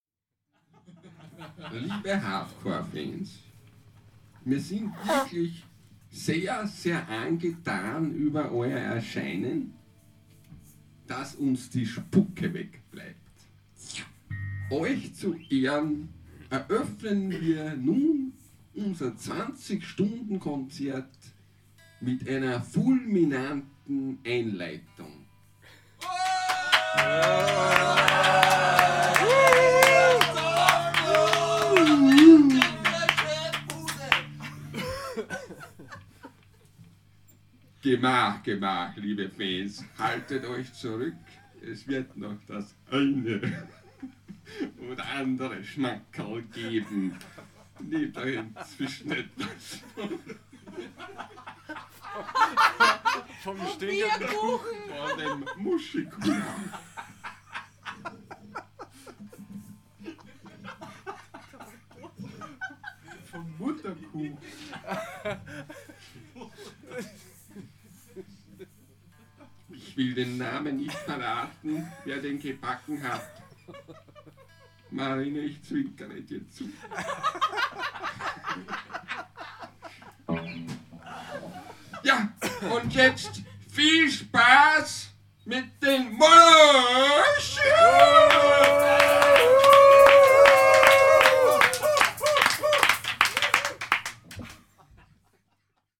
Weckruf und Morgengymnastik mit Musik
Konzertauftakt im Proberaum (mp3)